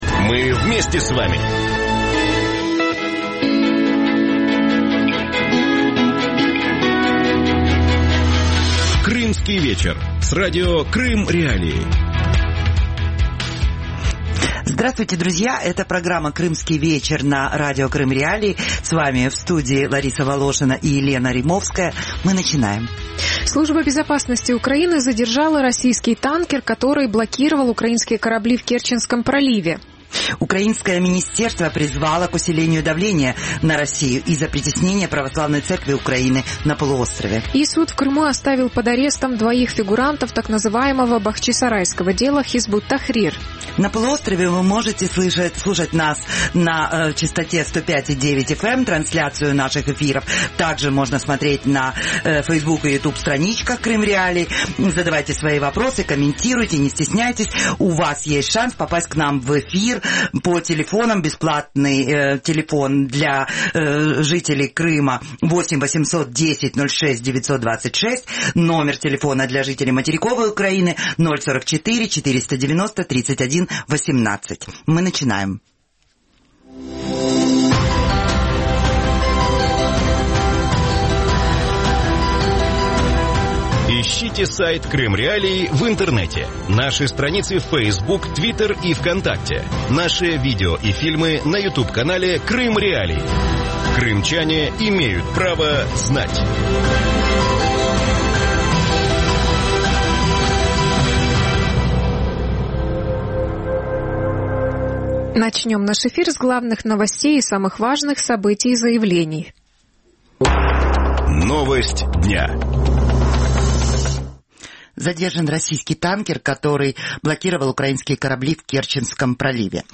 Что происходит в помещении Кафедрального собора? Почему в Крыму существуют препятствия в работе ПЦУ? Кто сможет защитить общину ПЦУ и крымских украинцев на аннексированном полуострове? Гости эфира: Климент, архиепископ Крымской епархии ПЦУ